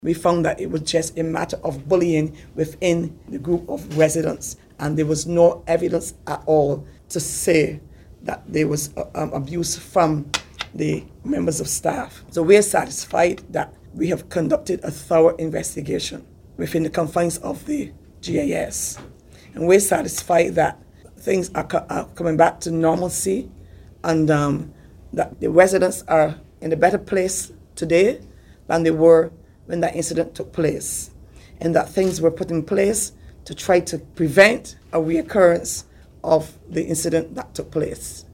at a news briefing this morning